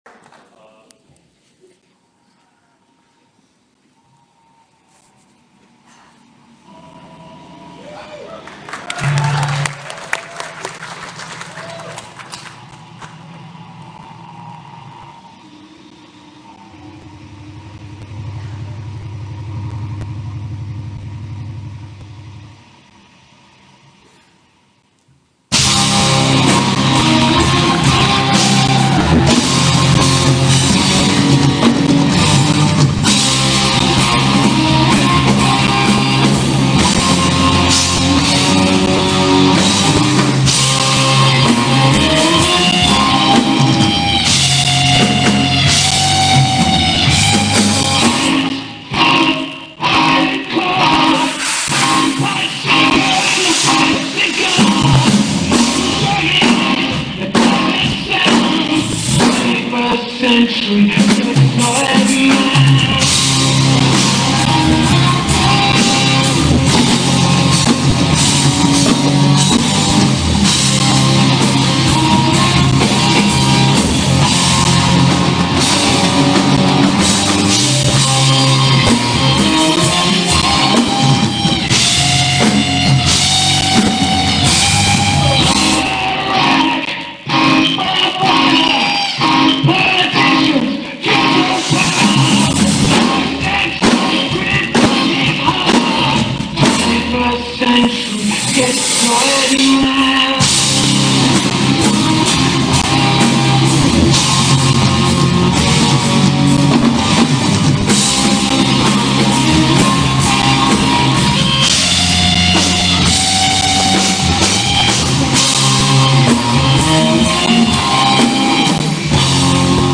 イアホンでノイズが多くても
トリプルドラムはオリジナリティ溢れるし